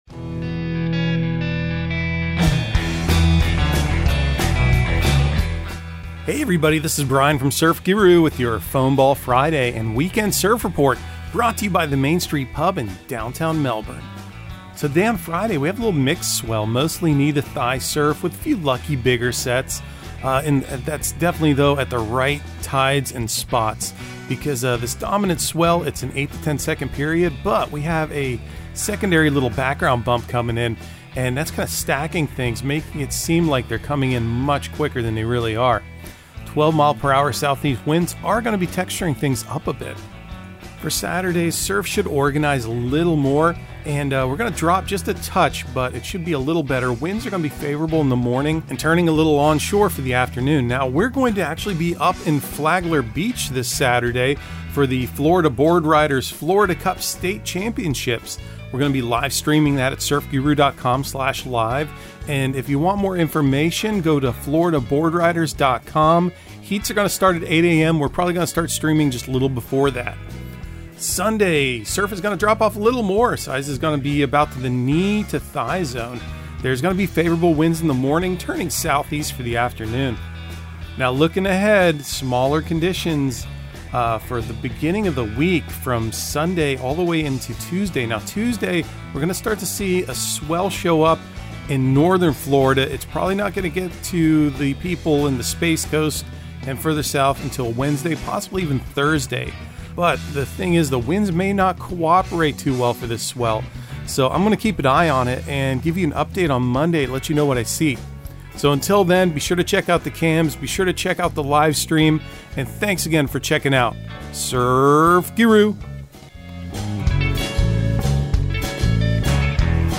Surf Guru Surf Report and Forecast 03/24/2023 Audio surf report and surf forecast on March 24 for Central Florida and the Southeast.
also sure to dig up some new music that will get your feet groovin'.